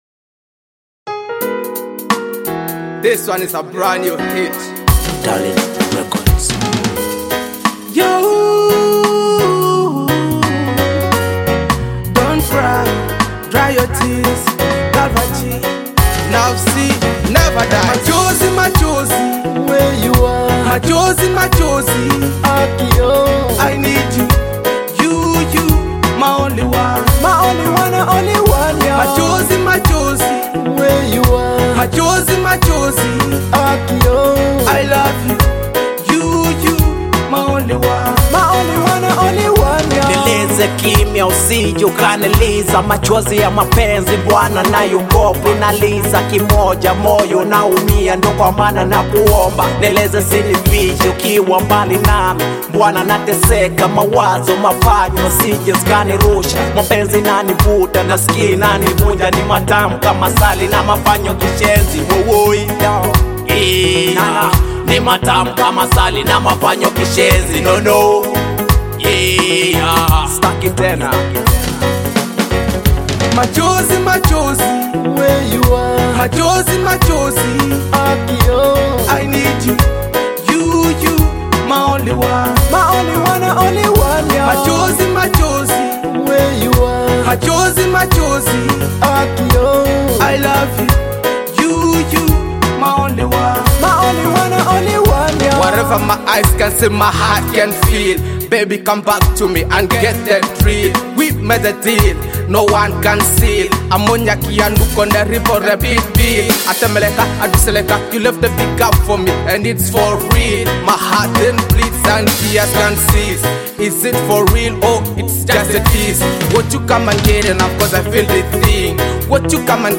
a captivating blend of rhythmic beats and powerful vocals.